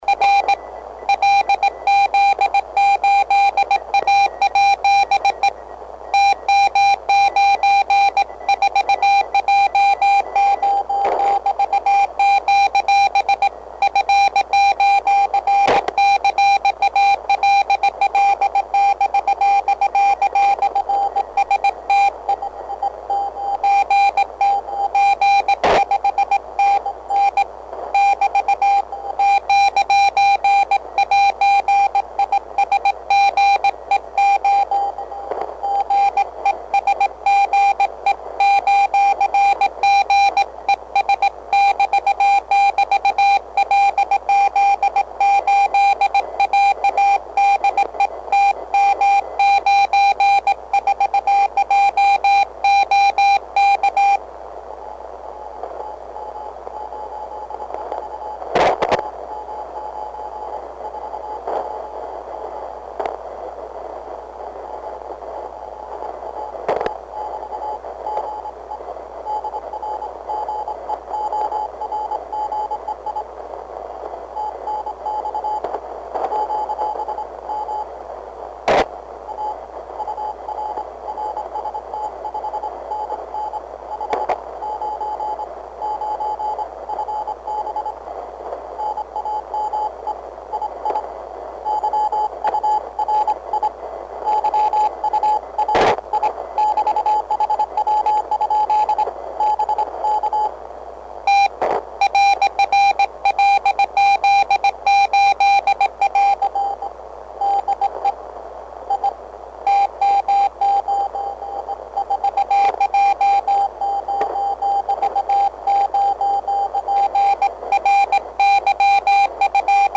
Выкладываю запись приема телеграфного QSO на 20м, первая часть с полосой 500Гц - вторая 200Гц. Запись делал во время сильной грозы, в наушниках не услышал раскаты грома.
Все таки паршивое звучание у этой поделки...Звук как у дырявого советского динамика...
Немного перегружен динамик, но это нормально, учитывая миниатюрный размер.